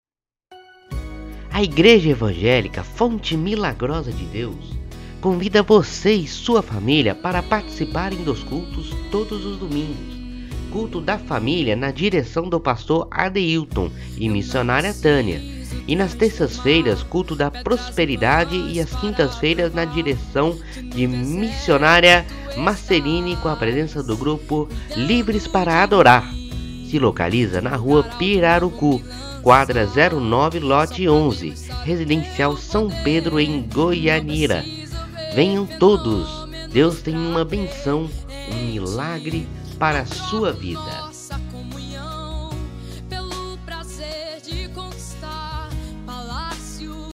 CARRO DE SOM